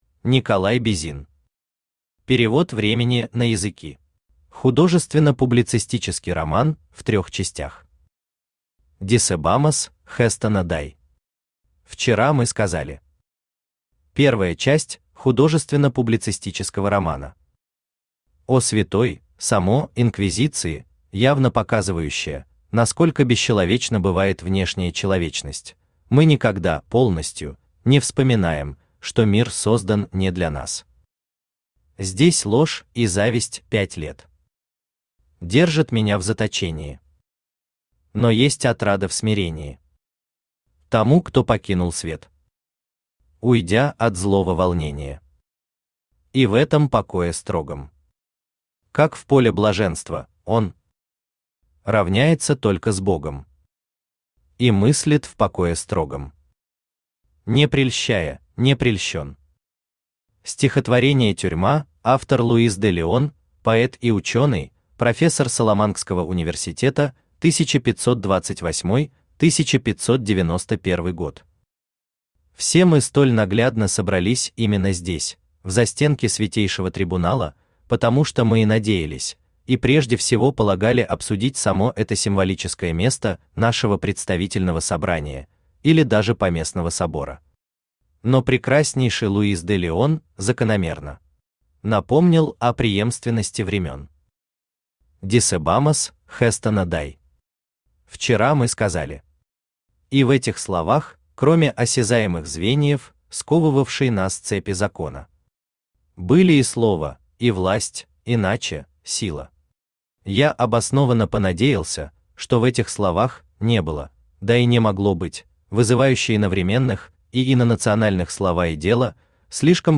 Aудиокнига Перевод времени на языки Автор Николай Бизин Читает аудиокнигу Авточтец ЛитРес.